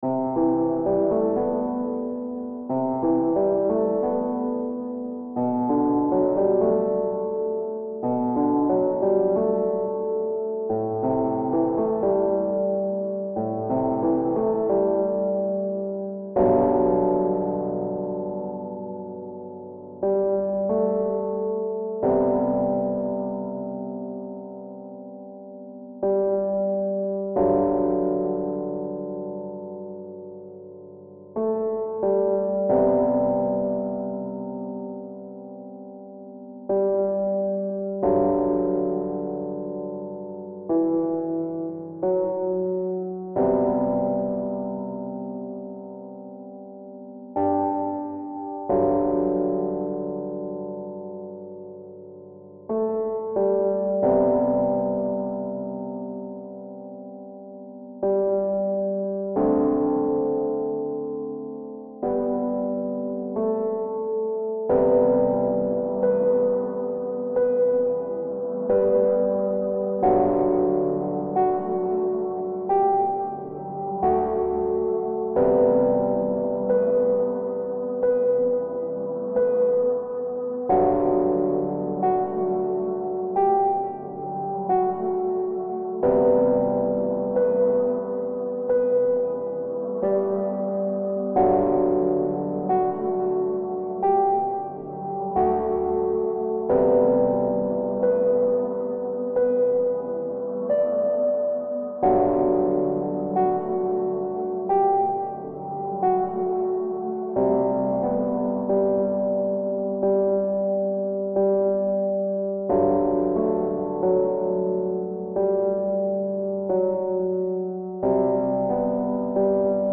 Genre: International pop
Version: Instrumental